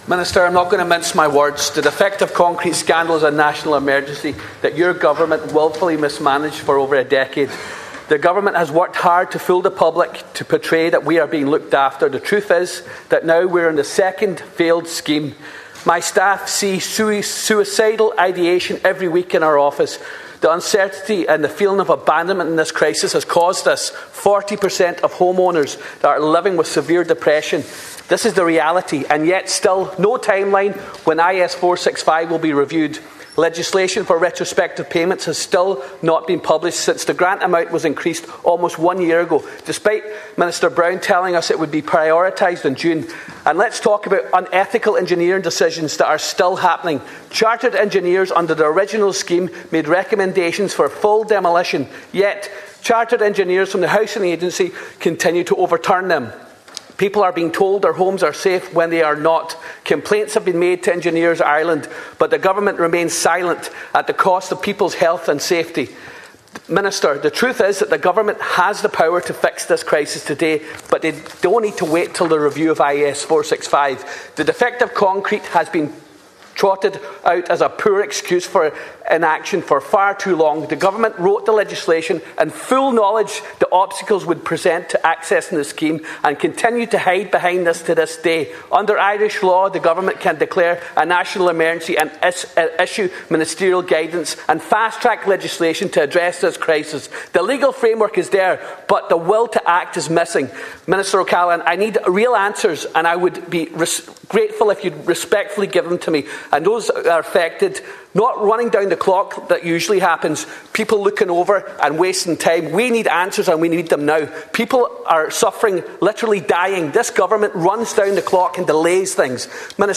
Donegal Deputy Charles Ward told the Dail 40% of impacted homeowners are living with severe depression, while the Government continues to claim people are being looked after.
Justice Minister Jim O’Callaghan responded that if unethical practices are being pursued, that should be reported..……..